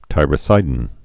(tīrə-sīdn)